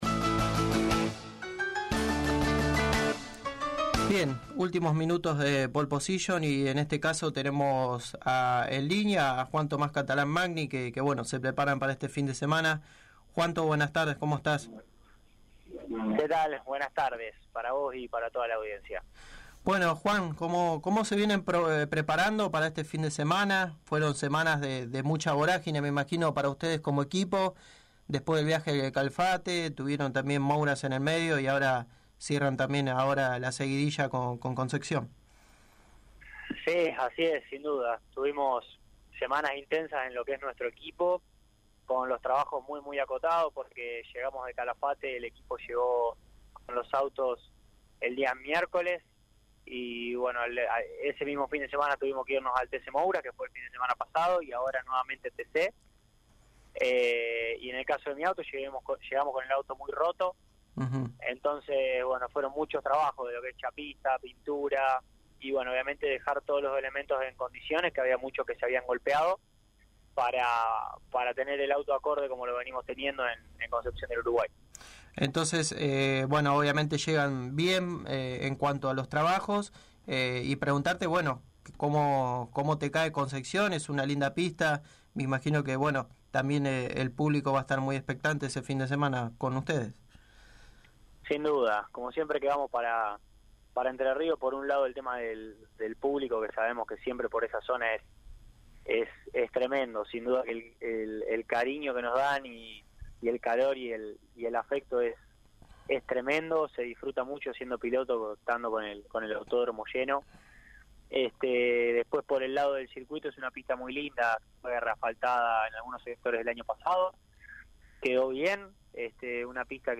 El arrecifeño pasó por los micrófonos de Pole Position y habló de como se vienen preparando junto al equipo para una nueva fecha del Turismo Carretera en Concepción del Uruguay.